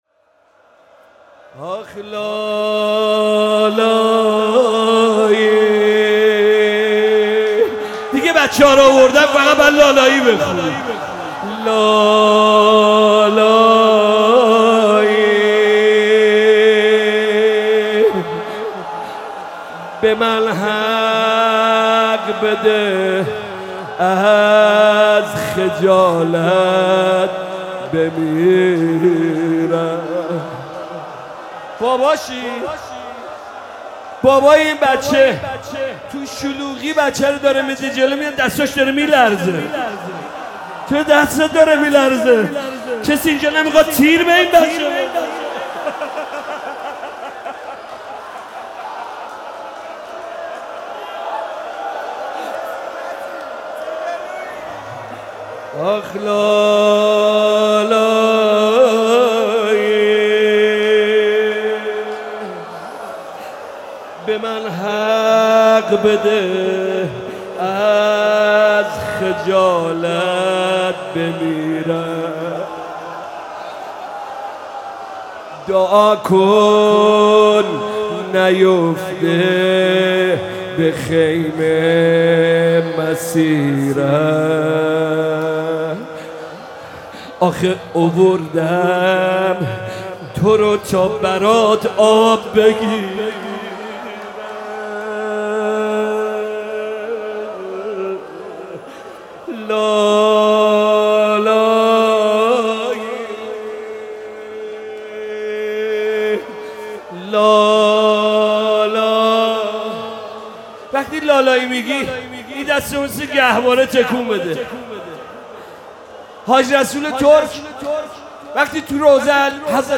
محرم 98 شب هفتم - زمزمه - به من حق بده از خجالت بمیرم
زمزمه حاج محمدرضا طاهری محرم 98 شب هفتم